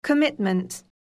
듣기영국 [kəmítmənt]